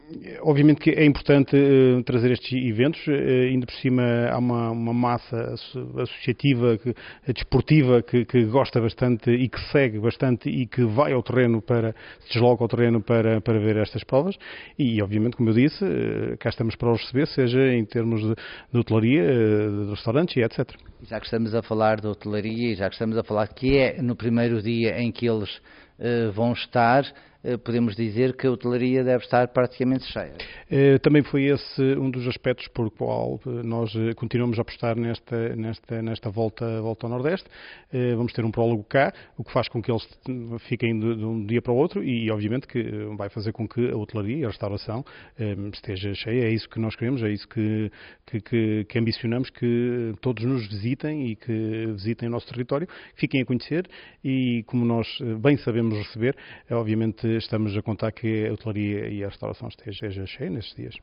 Para o anfitrião da etapa inaugural, o presidente da Câmara Municipal de Macedo de Cavaleiros, Sérgio Borges, o impacto económico será sentido sobretudo na restauração e no alojamento, que poderão registar uma procura significativa: